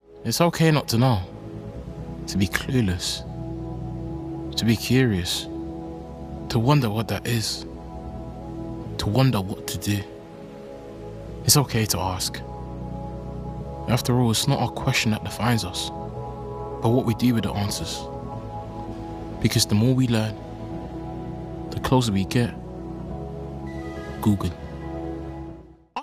Voice Reel
Commercial - Google - Distinctive, Strong, Calm